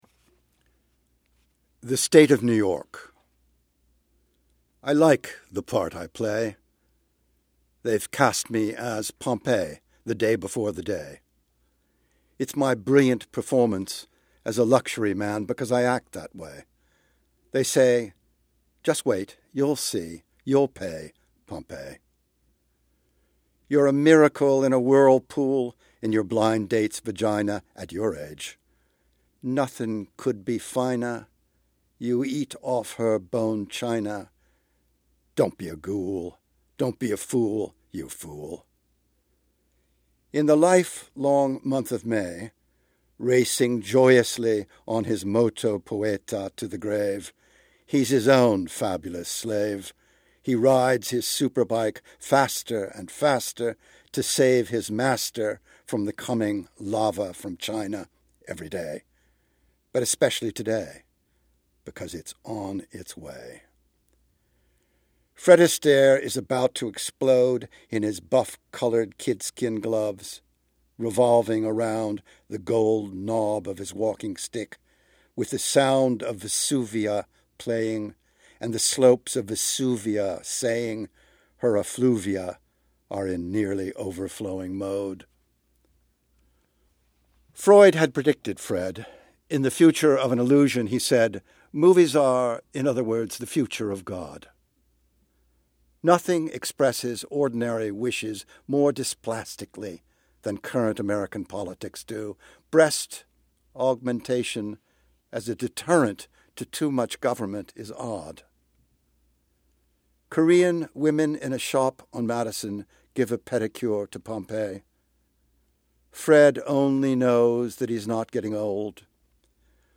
Poet Frederick Seidel reads the poem "The State of New York," from his collection Nice Weather, published in September by Farrar, Straus and Giroux.